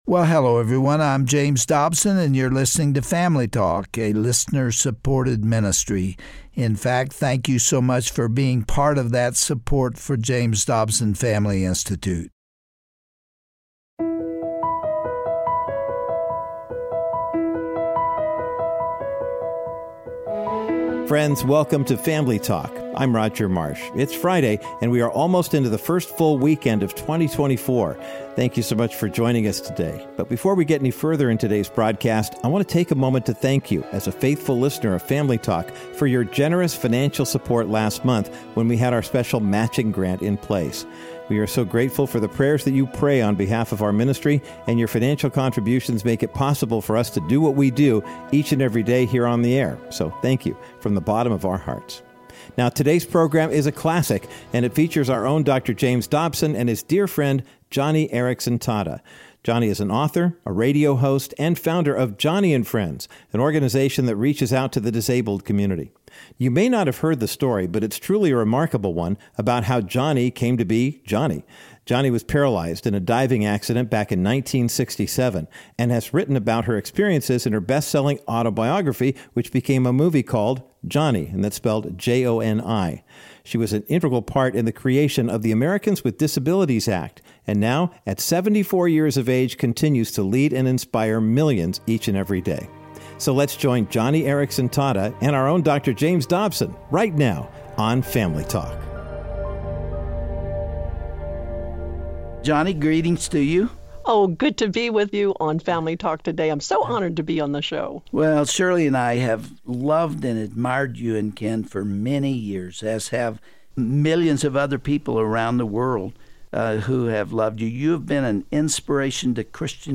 Her name is Joni Eareckson Tada, and on today’s classic edition of Family Talk, Dr. James Dobson interviewed her in 2010 to discuss her battle with cancer. It's a tender conversation that will uplift your spirit and provide courage as only God can do.